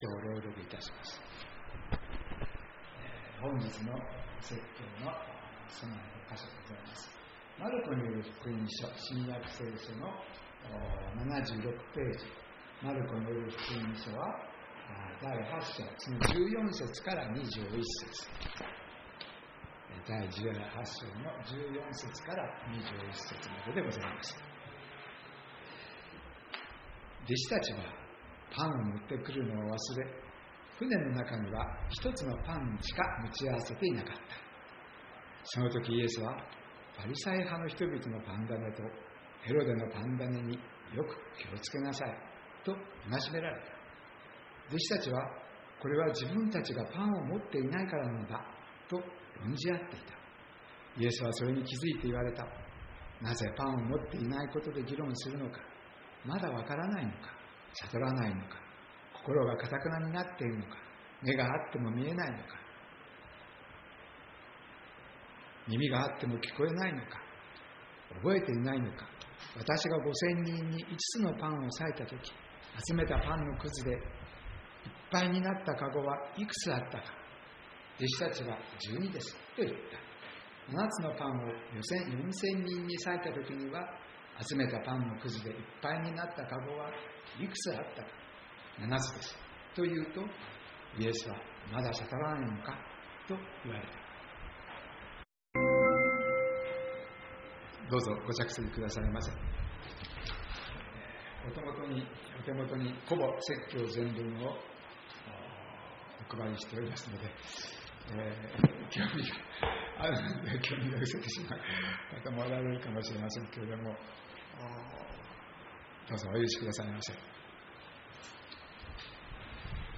まだ悟らないのか 宇都宮教会 礼拝説教
栃木県鹿沼市 宇都宮教会